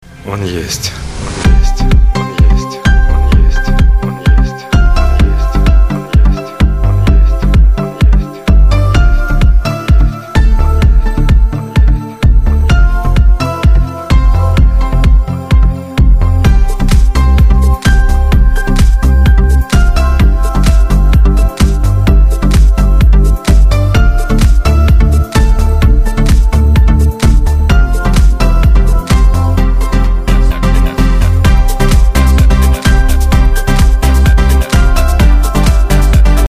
Танцевальные [47]